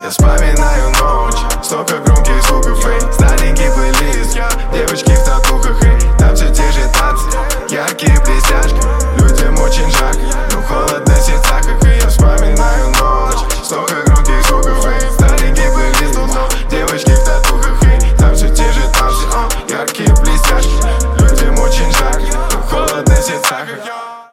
• Качество: 128, Stereo
рэп